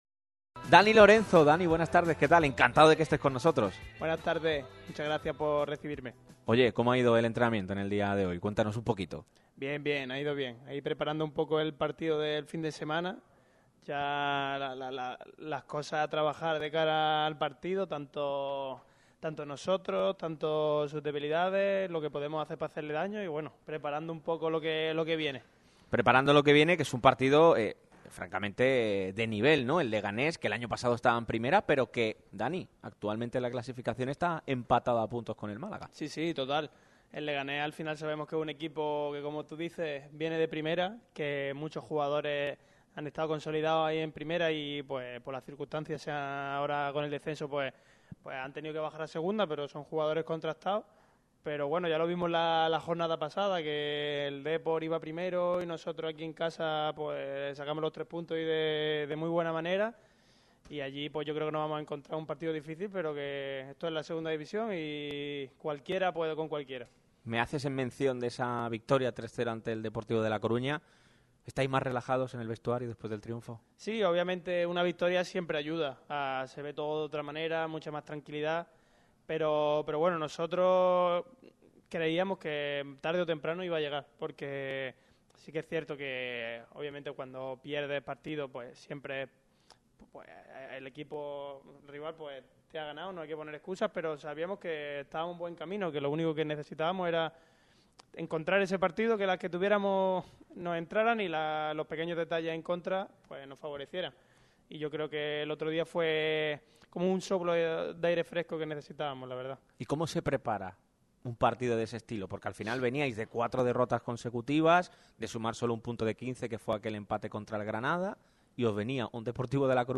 Día de entrevistón en Radio MARCA Málaga.